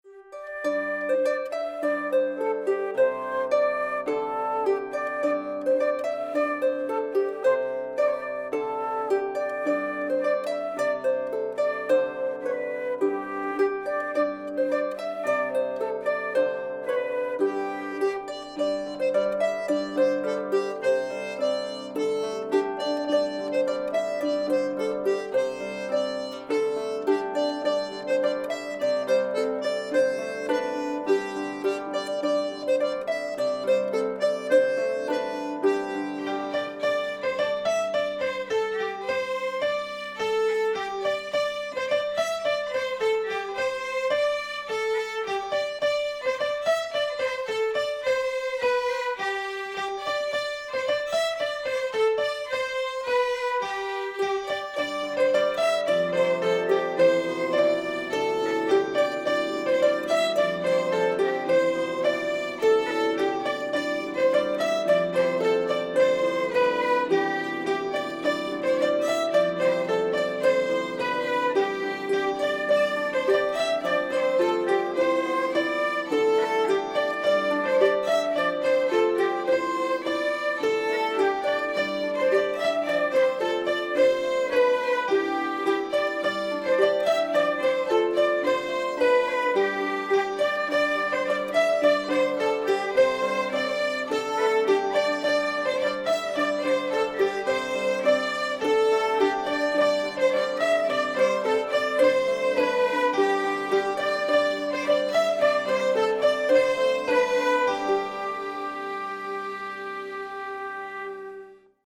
Les enregistrement de groupes d’élèves ont été effectués à la Carène, en avril 2016.
Un ton dañs Sizun, par les Ecoliers également.